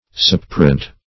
suppurant - definition of suppurant - synonyms, pronunciation, spelling from Free Dictionary
suppurant - definition of suppurant - synonyms, pronunciation, spelling from Free Dictionary Search Result for " suppurant" : The Collaborative International Dictionary of English v.0.48: Suppurant \Sup"pu*rant\, n. (Med.)